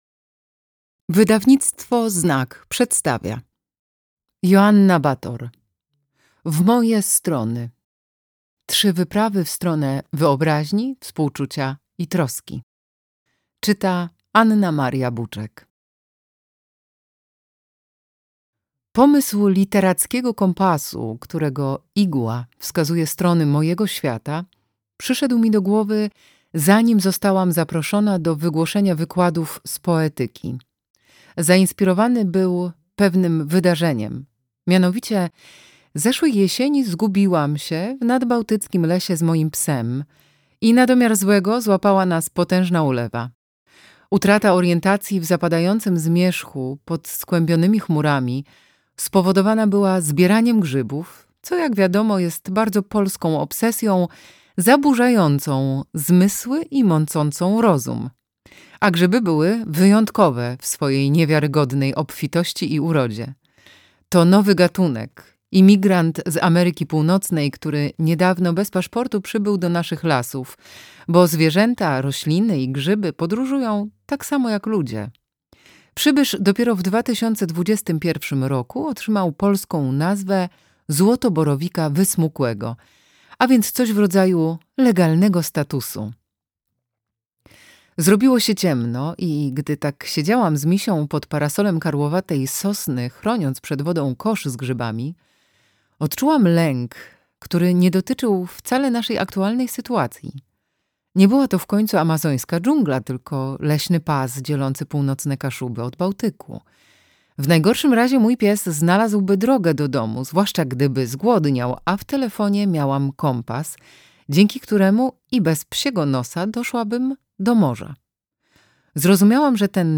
W moje strony. Trzy wyprawy w stronę wyobraźni, współczucia i troski - Joanna Bator - audiobook